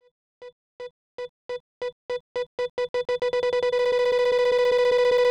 BombBeep.wav